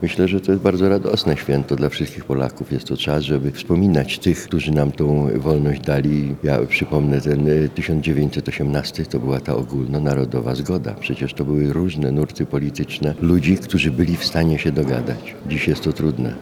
Uroczysta sesja Rady Miasta w Trybunale Koronnym rozpoczęła obchody Narodowego Święta Niepodległości w Lublinie.